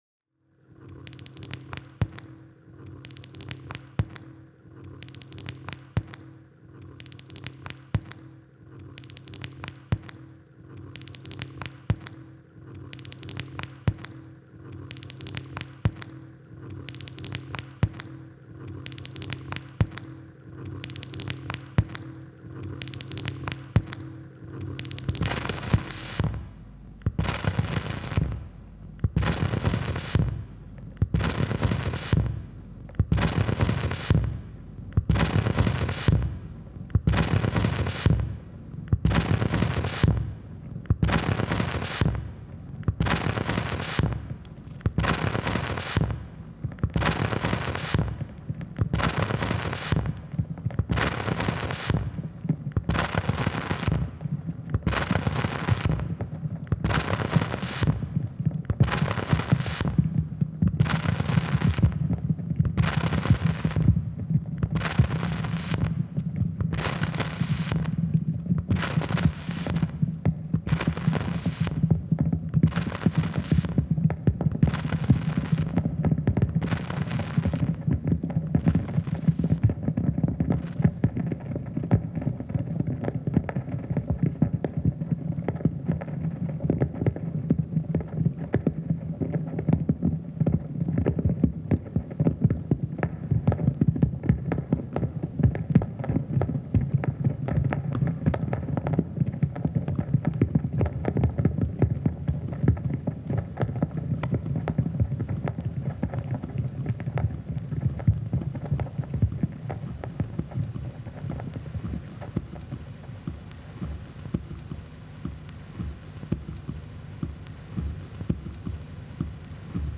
Surface Bruit is not the type of sound we usually try to reproduce.
surface-noise-st-leoanrds_001.mp3